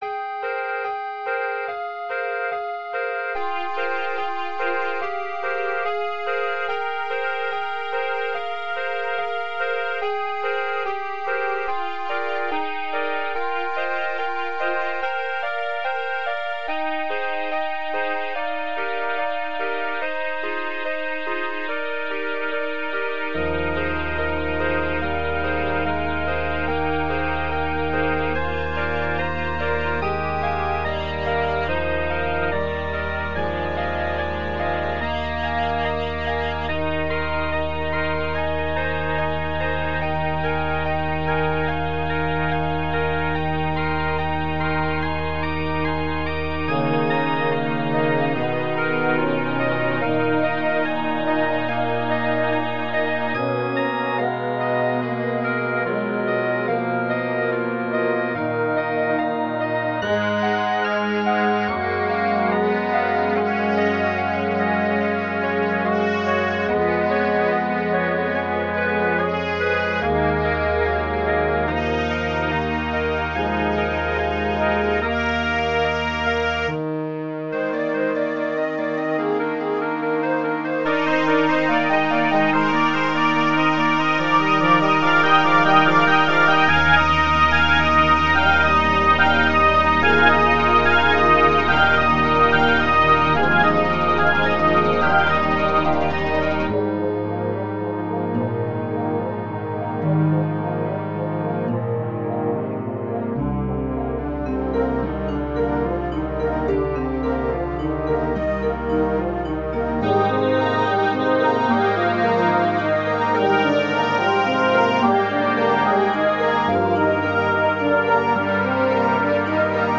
Wind Orchestra
toccata_fugue_wind.ogg